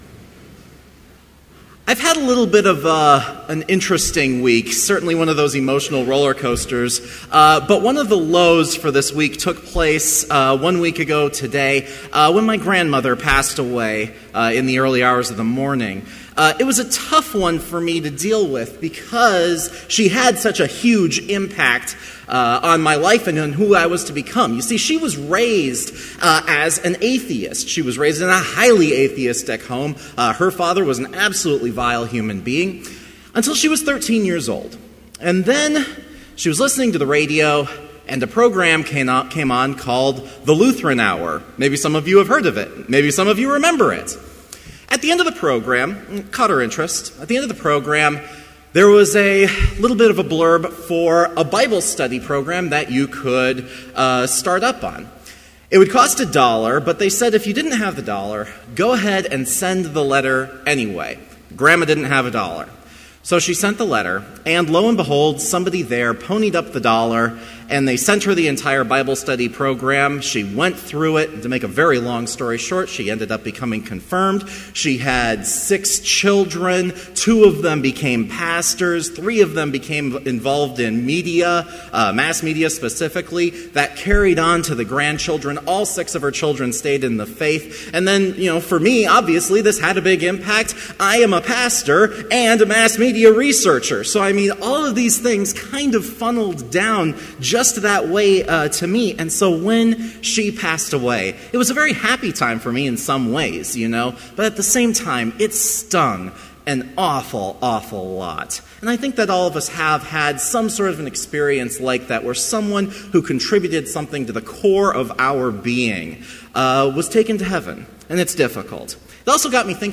Complete service audio for Chapel - April 3, 2014